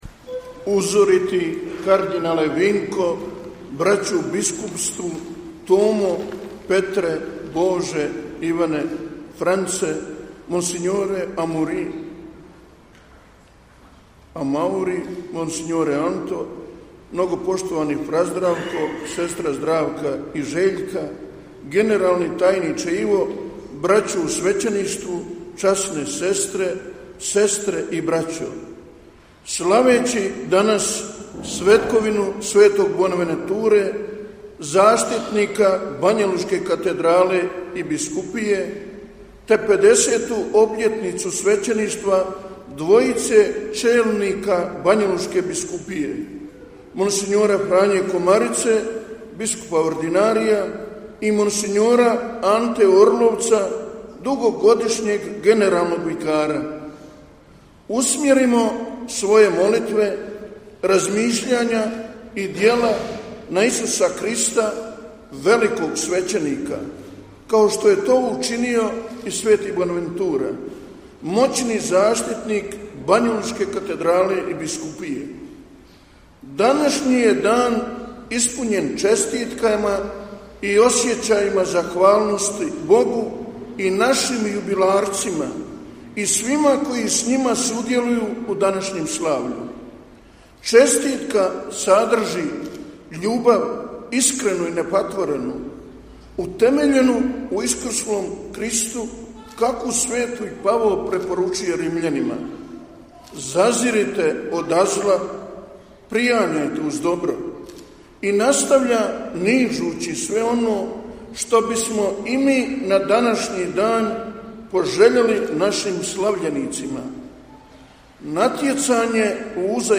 Prije početka svečanog Euharistijskog slavlja, na svetkovinu sv. Bonaventure, nebeskog zaštitnika Banjolučke biskupije i katedrale u petak, 15. srpnja 2022. u katedrali u Banjoj Luci, kojim je predsjedao biskup banjolučki mons.